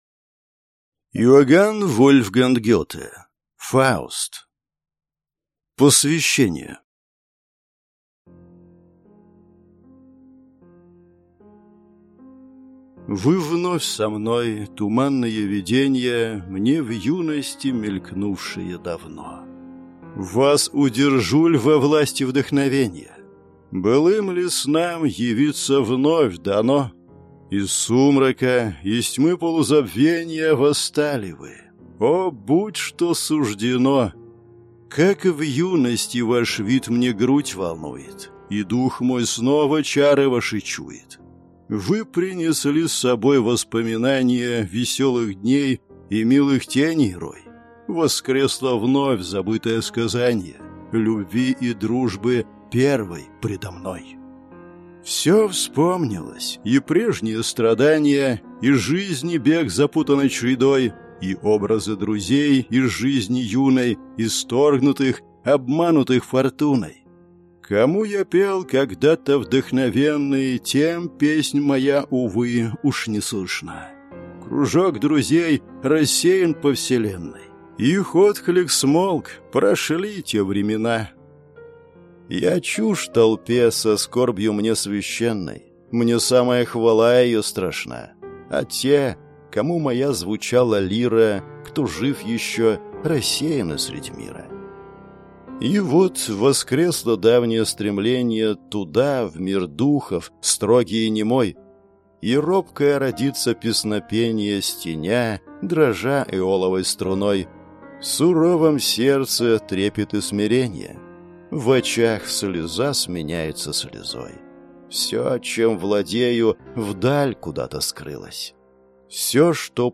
Aудиокнига